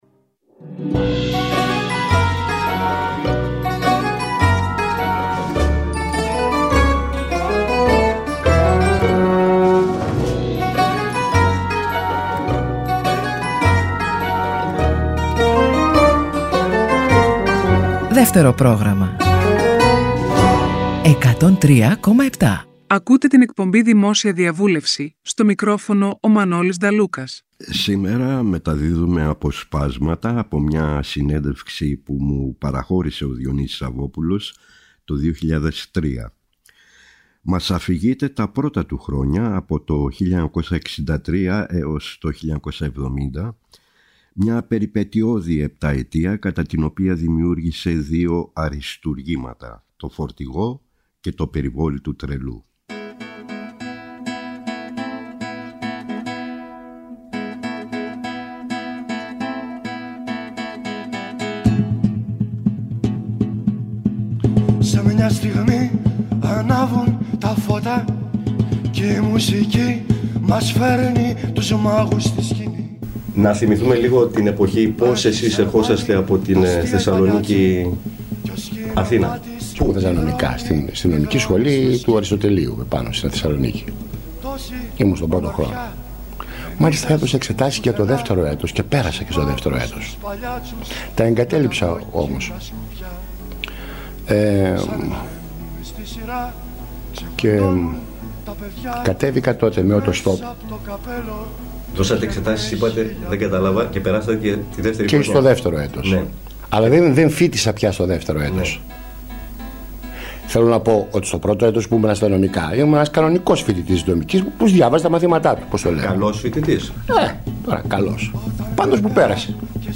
Αποσπάσματα συνεντεύξεων του Διονύση Σαββόπουλου